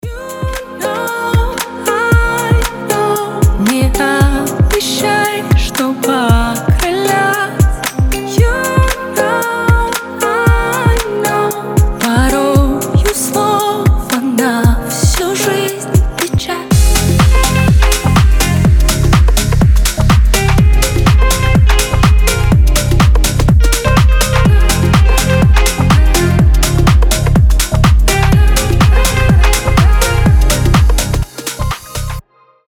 • Качество: 320, Stereo
deep house
мелодичные
женский голос
ремиксы